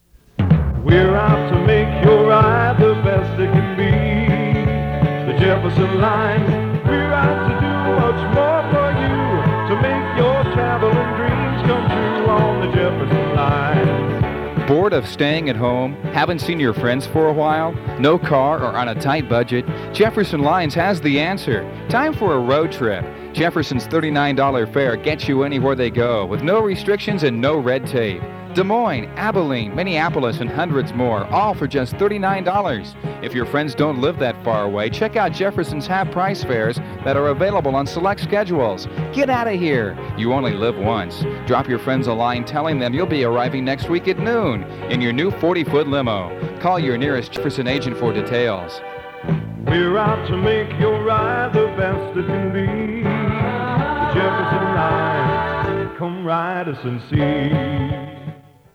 Jefferson Lines radio spot, ride us and see, undated. 1 master audio file (58 seconds): WAV (4.9 MB) and 1 user audio file: MP3 (919 KB).